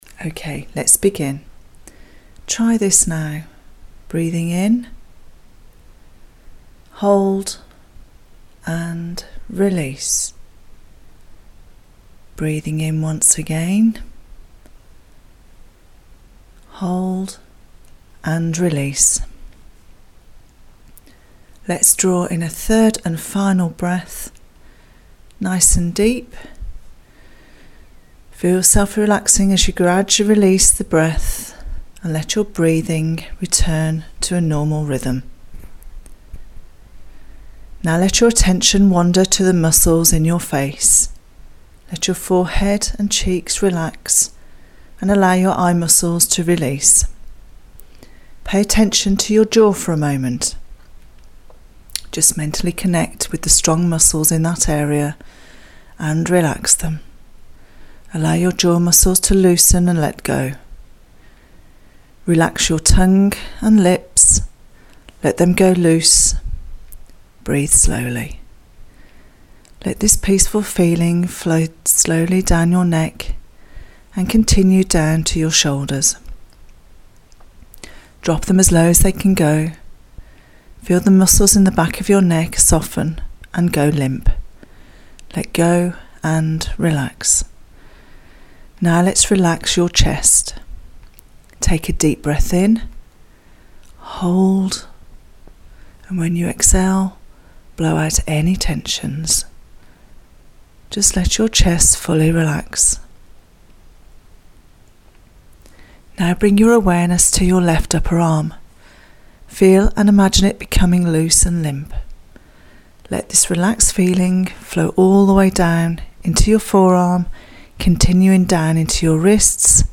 Guided Sleep Mediation for Insomnia
FromWithin-Sleep-Meditation.mp3